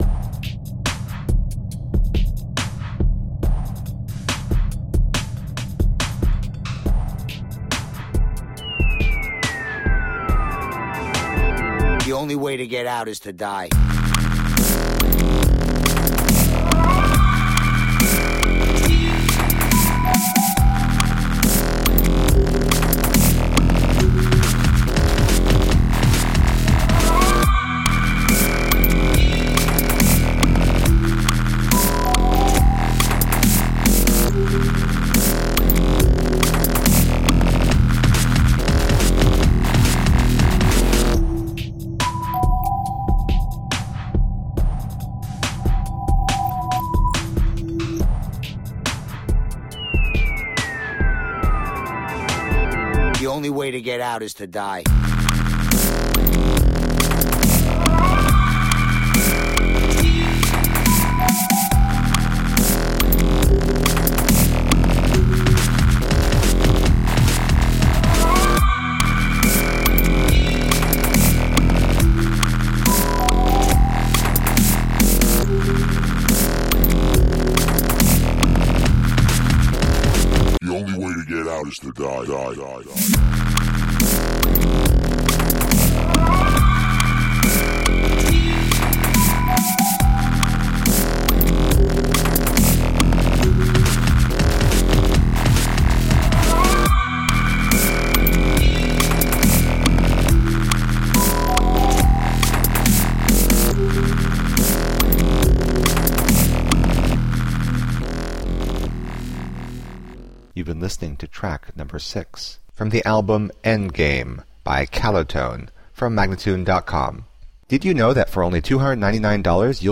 Melodic dubstep and heavy electronic stimulation.
Tagged as: Electronica, Techno